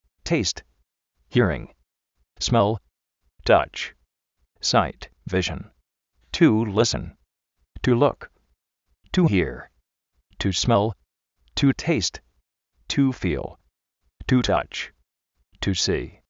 téist
jí:ring
smél
tách
sáit, víshn